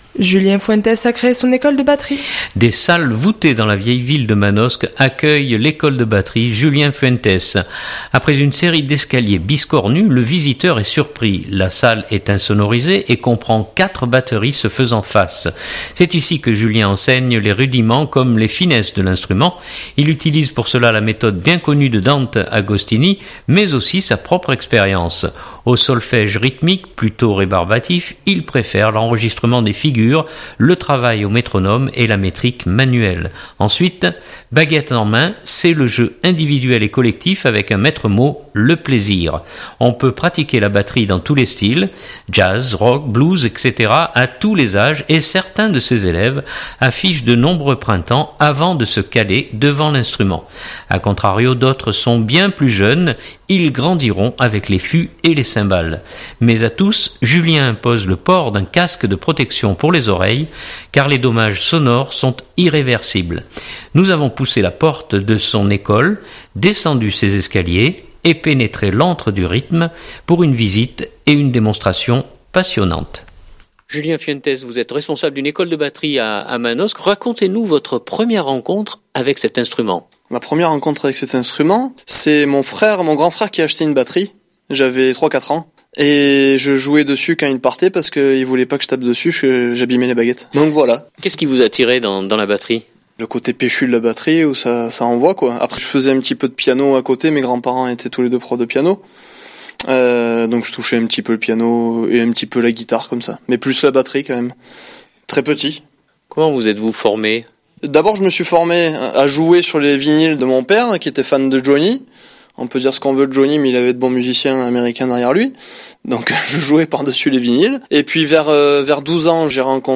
Nous avons poussé la porte de son école, descendu ses escaliers et pénétré l’antre du rythme pour une visite et une démonstration passionnantes… écouter